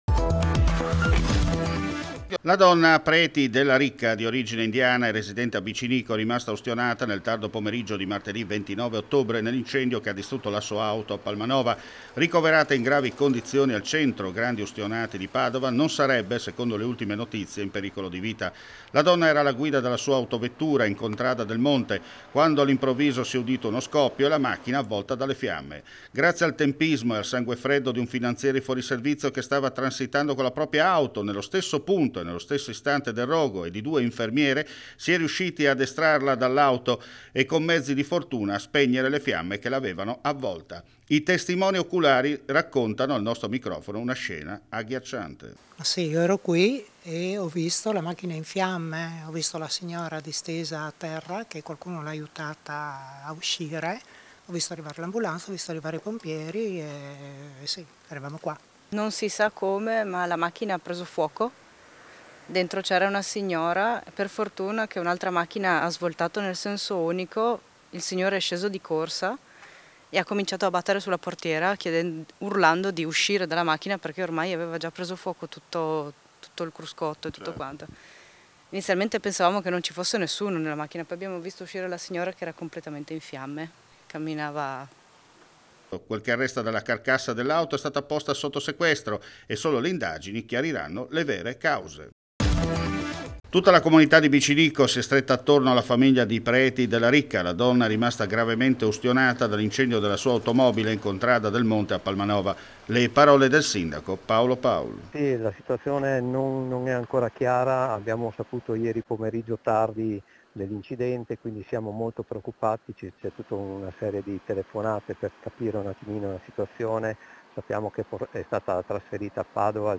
FRIULITV GIORNALE RADIO: LE AUDIONOTIZIE DAL FRIULI VENEZIA GIULIA